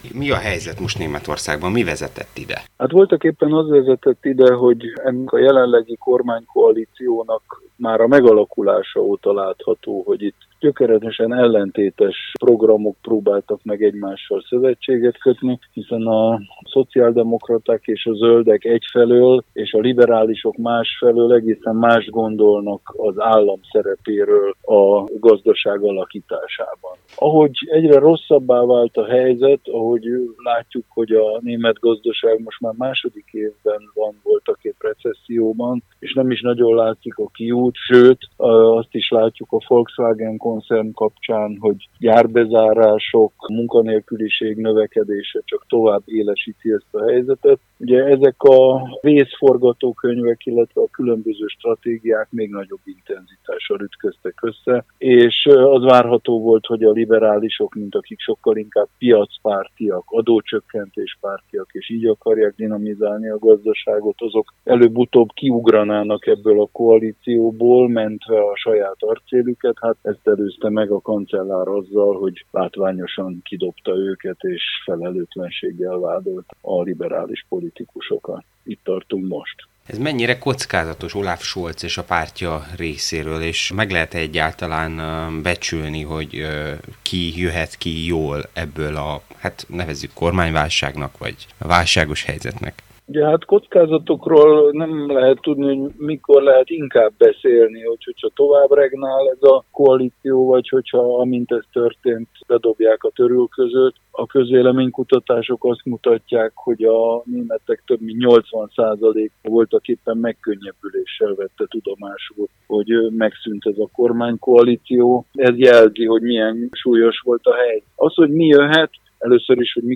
A német lakosok 80 százaléka megkönnyebbült az eddigi kormány felbomlása után, az azonban reális forgatókönyv, hogy a következő választások után is politikai patthelyzet alakuljon ki Németországban – mondta el a Marosvásárhelyi Rádiónak Prőhle Gergely volt német és svájci nagykövet, a Nemzeti Közszolgálati Egyetem John Lukacs Intézetének programigazgatója.